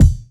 Kick24.wav